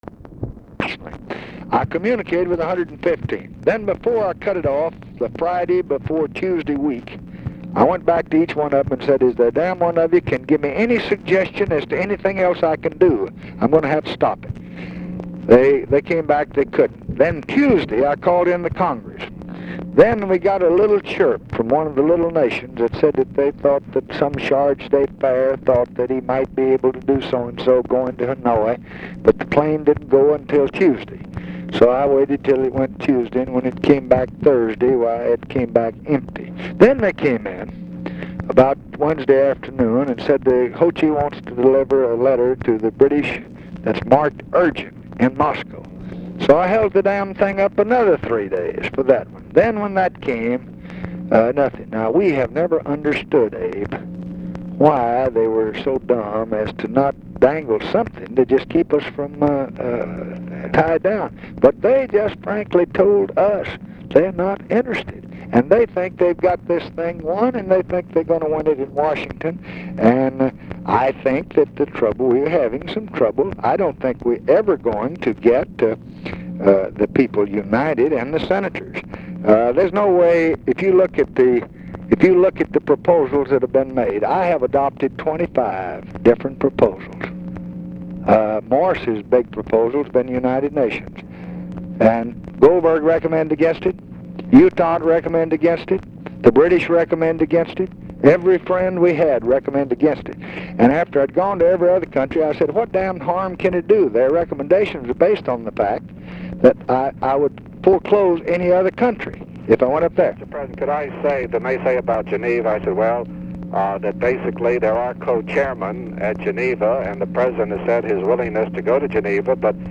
Conversation with ABRAHAM RIBICOFF, February 14, 1966
Secret White House Tapes